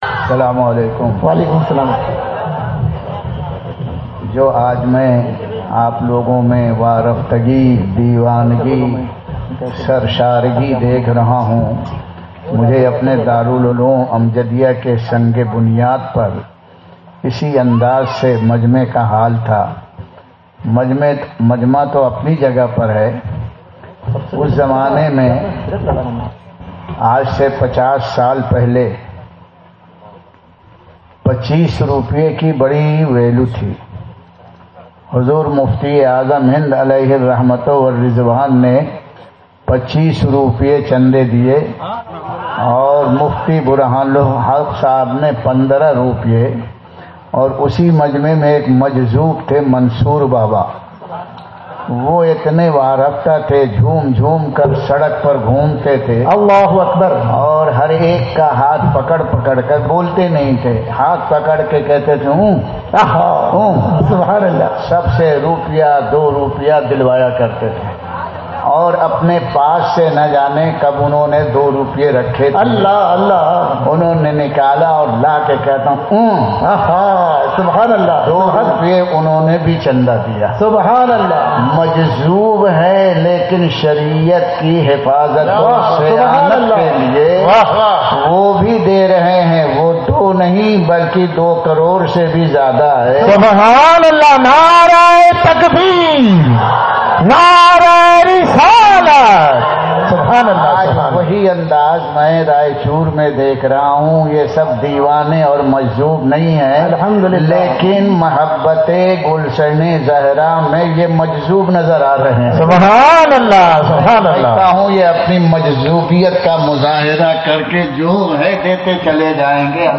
Speeches